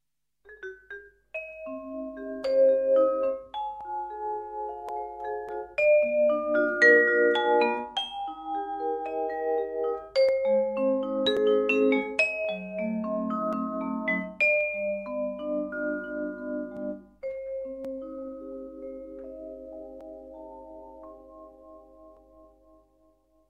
Recursos para Percusión
Vibráfono.mp3